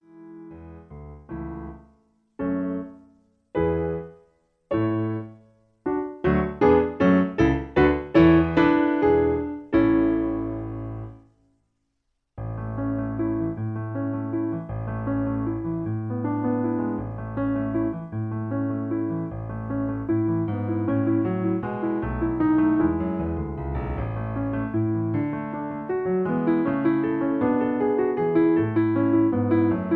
In A. Piano Accompaniment